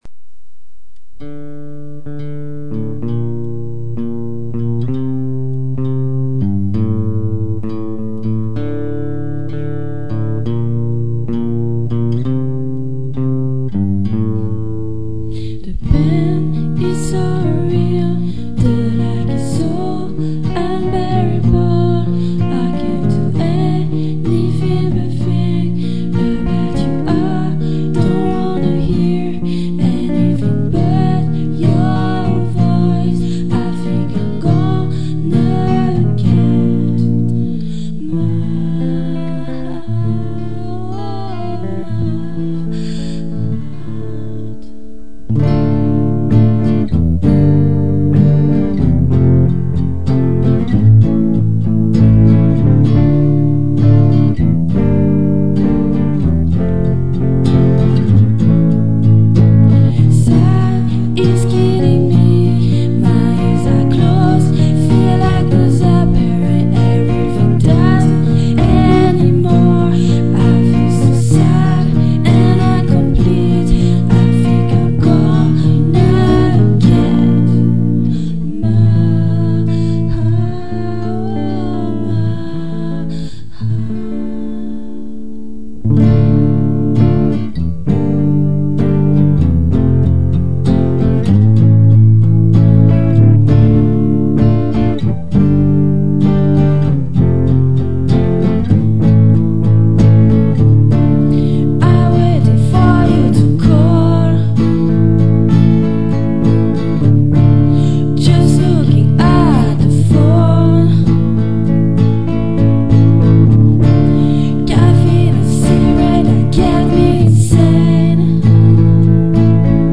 Acoustique